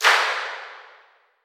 FX_Stomp.wav